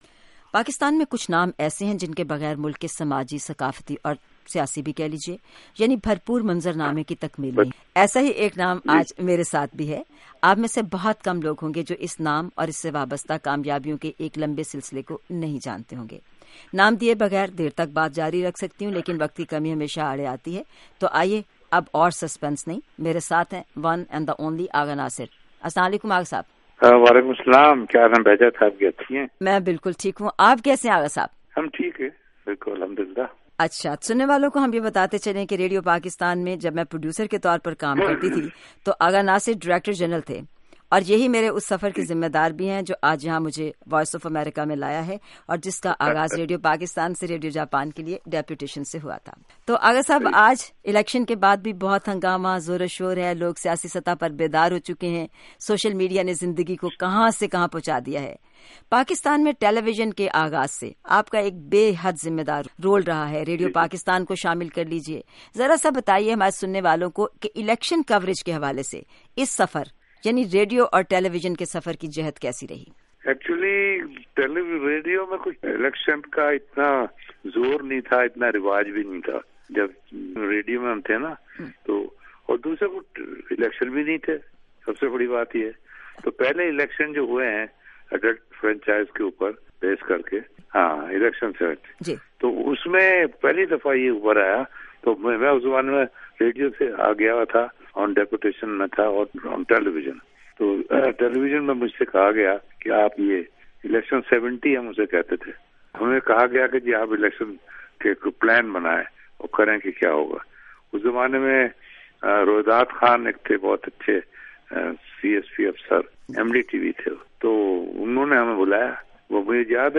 آغا ناصر کے ساتھ خصوصی گفتگو